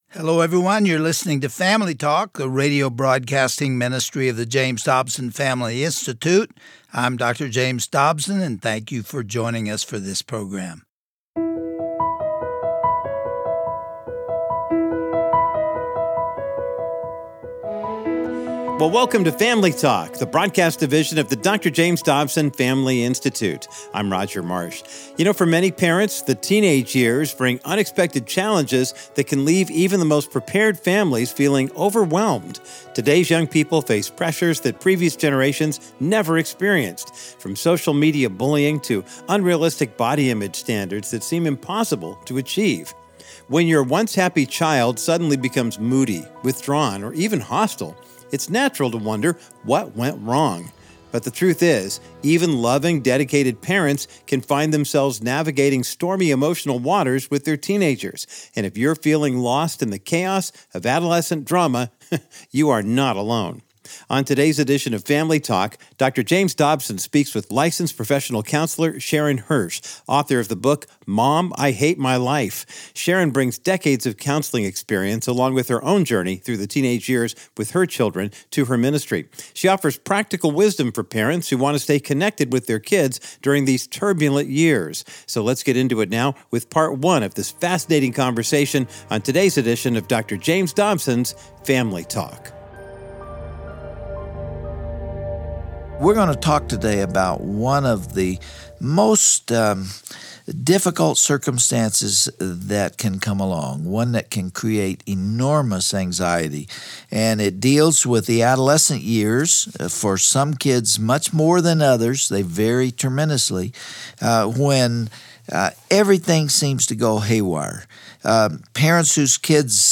a radio broadcasting ministry